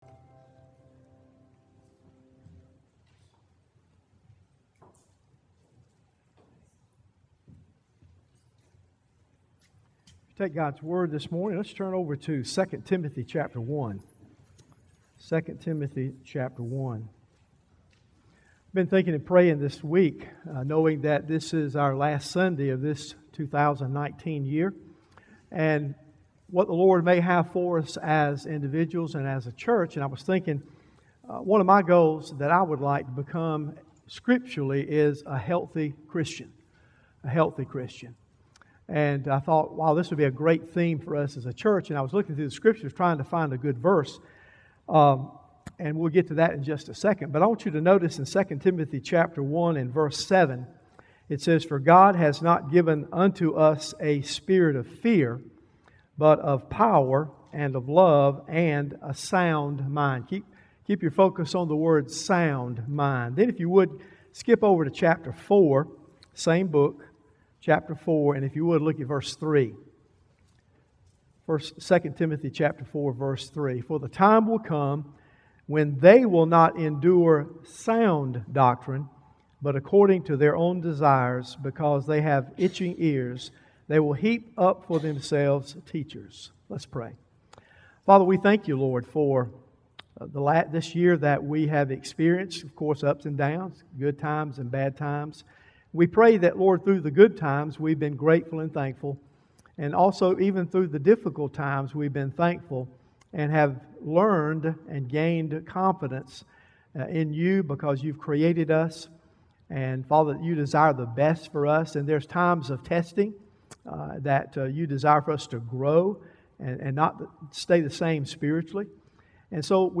Arlington Baptist Church Sermons